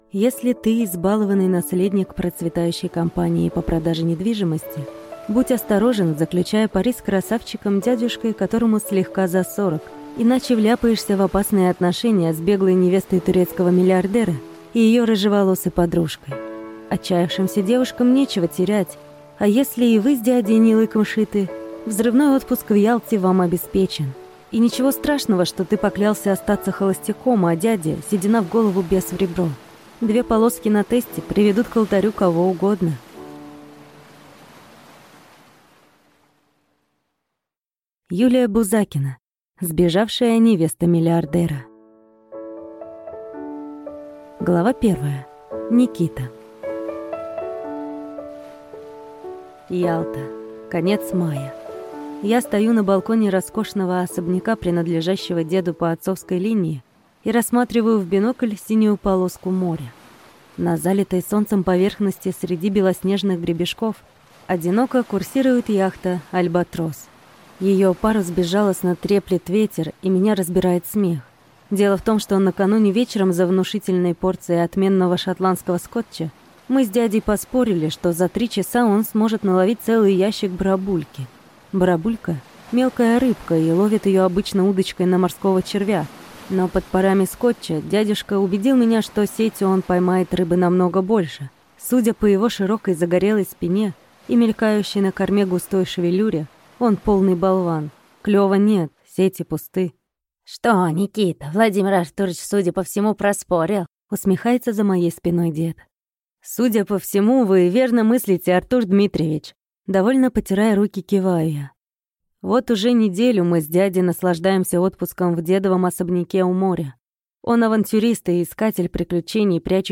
Аудиокнига Сбежавшая невеста миллиардера | Библиотека аудиокниг